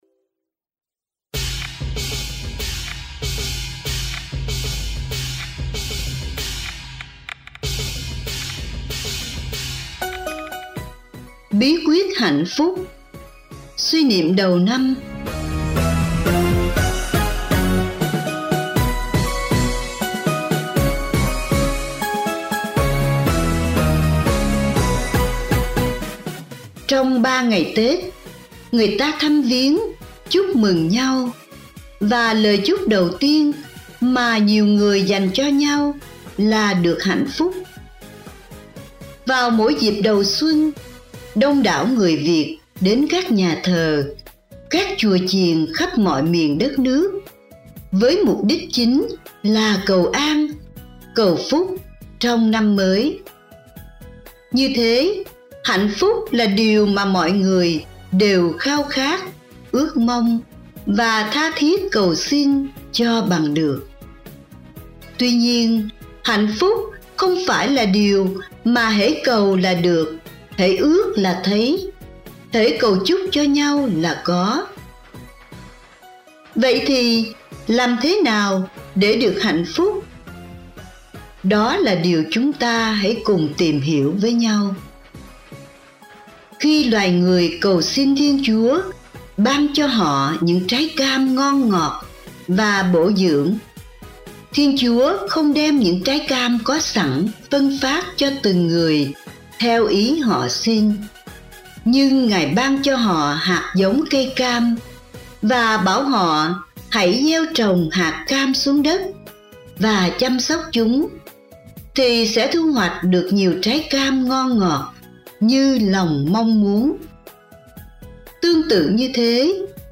(Suy niệm đầu năm)